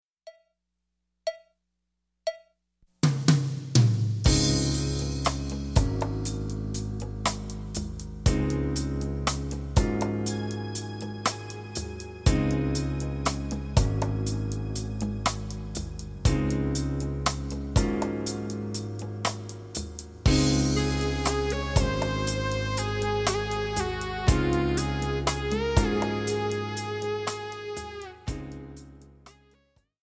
• Meer dan 18 play-along nummers.
• Instrumentgroep: Drums
• Instrumentatie: Drumset